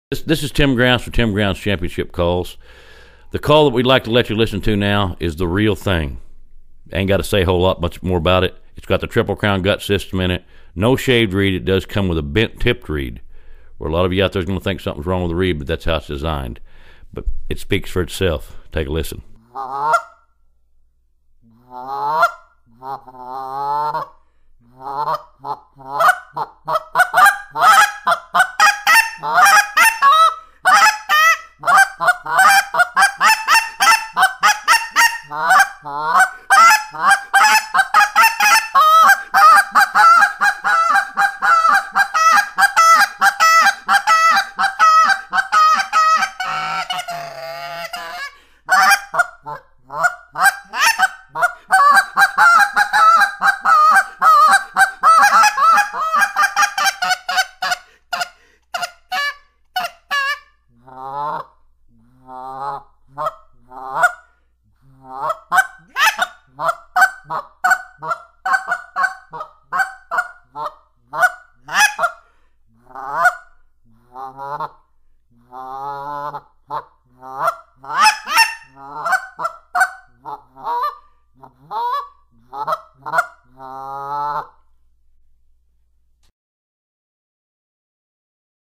Goose Calls
This 4.5in.  Real Thang gives a higher pitch tone due to the short insert.